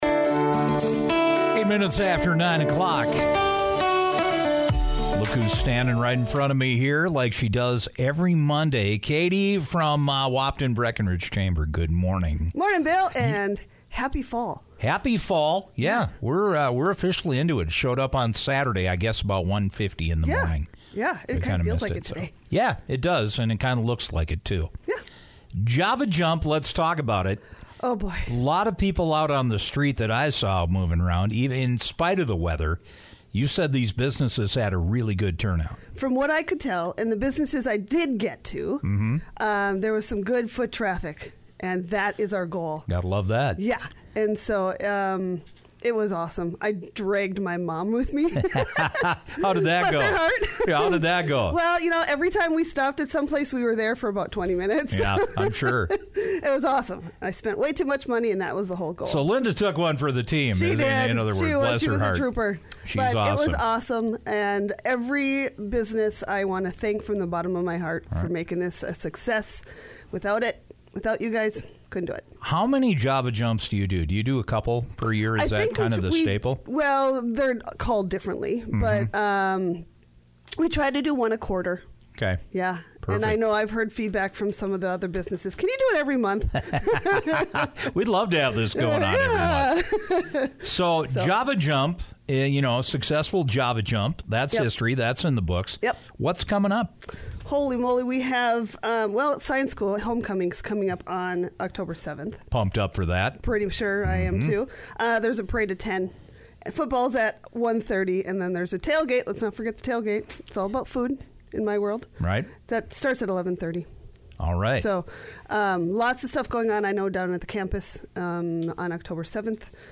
radio segment